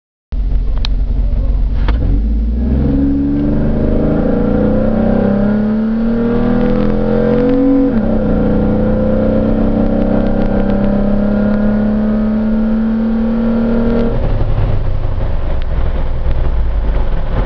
The intake resonance under wide open throttle is LOUD and actually trasfers into the dash and interior.
I recorded the exhaust with my IPAQ, from the drivers seat and all accessories turned off for the interior startup and the accelleration. The Exterior startup was recorded from about 10 feet behind the Jeep, about 2 feet off of the ground.
Interior startup //// Exterior startup and rev ////